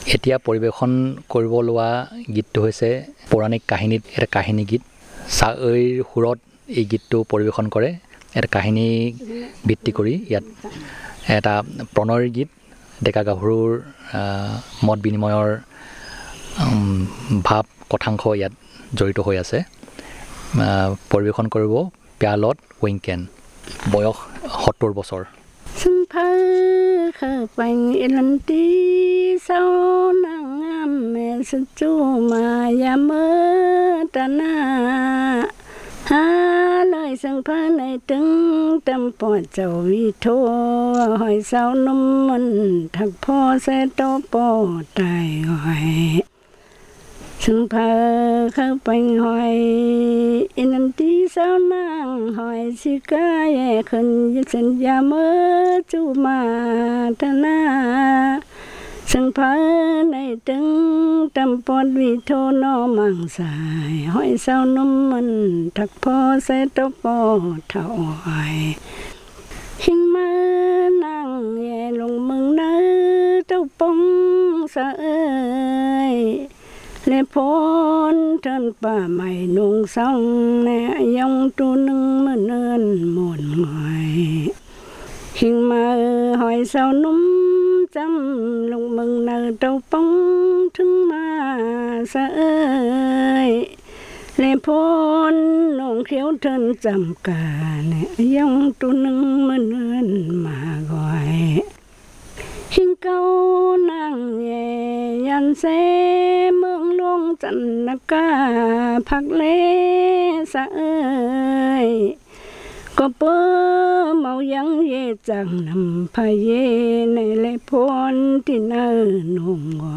Performance of a story song about love and feelings